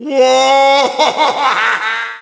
Wario hollering as he falls off in Mario Kart Wii.